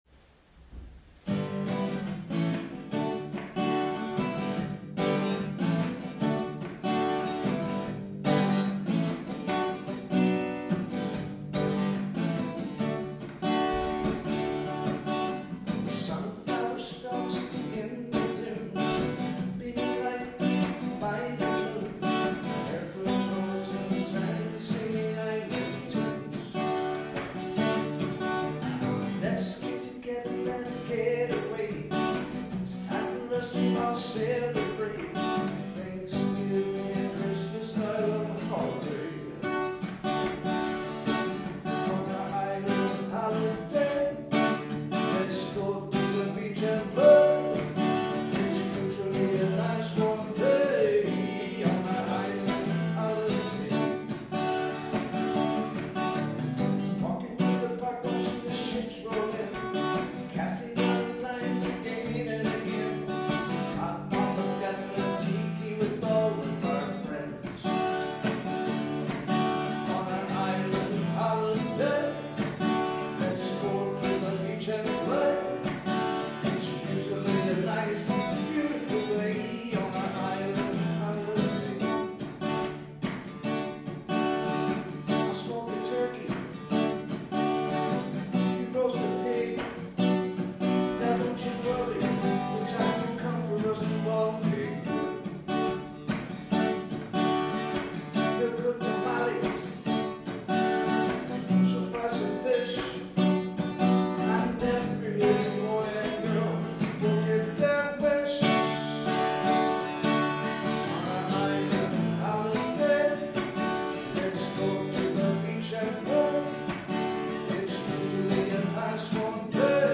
These music sessions were recorded in the Chapel on the Dunes.